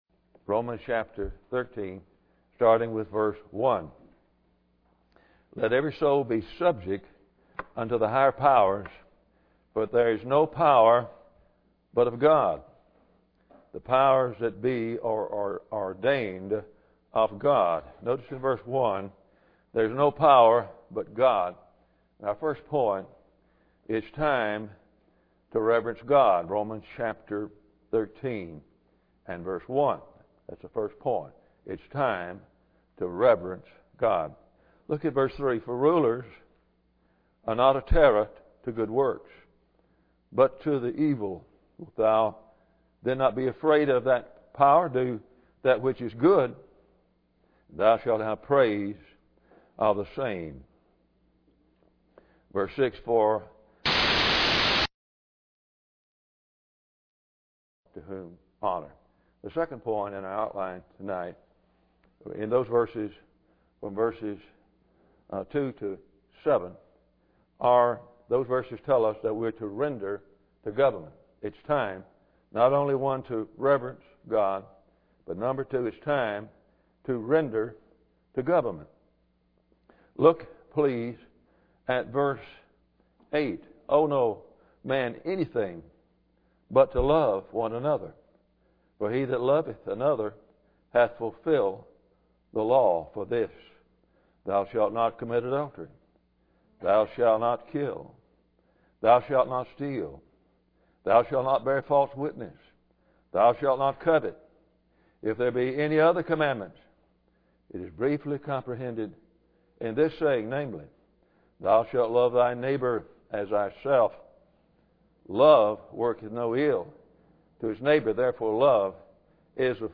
Romans 13:1 Service Type: Sunday Evening Bible Text